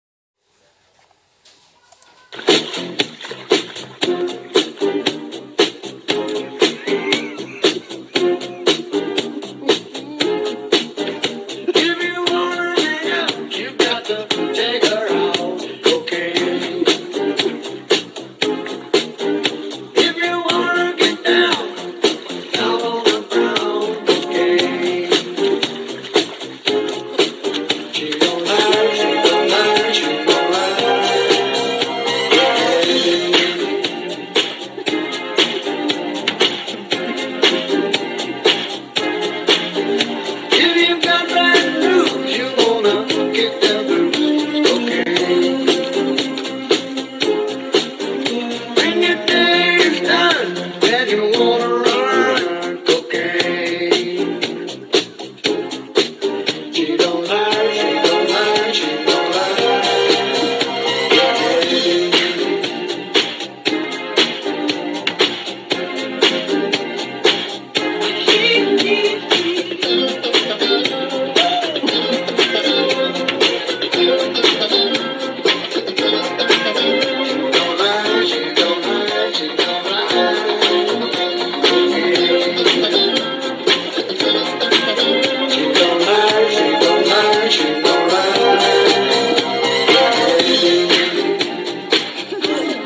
Извиняюсь за качество и благодарю всех, кто поучаствует.